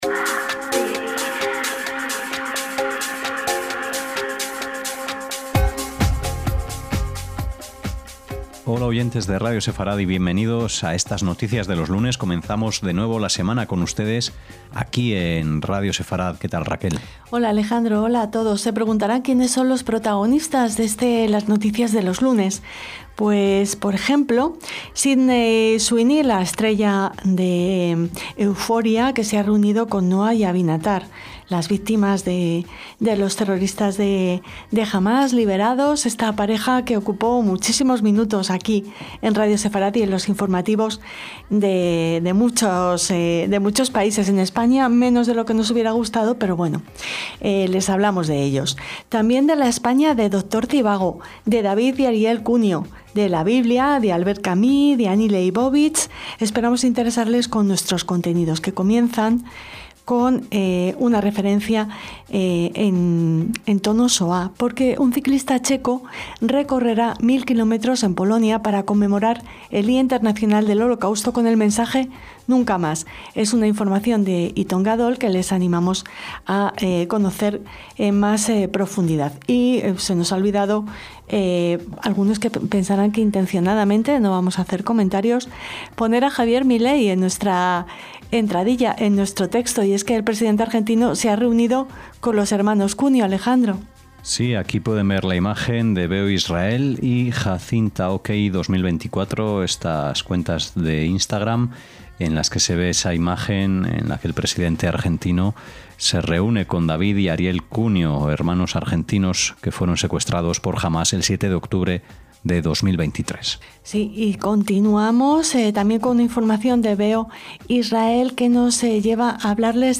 LAS NOTICIAS DE LOS LUNES - ¿Los protagonistas de este informativo con el que queremos animarles a comenzar la semana?